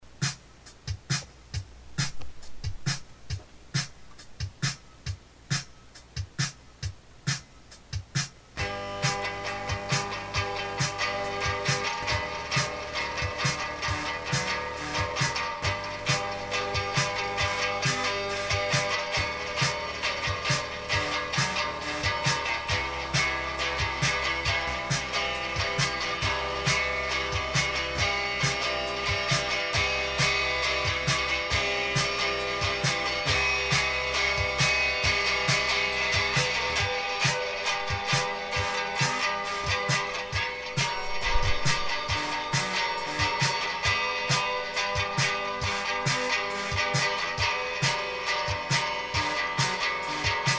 in_situ_-_fourth_song_sketch.mp3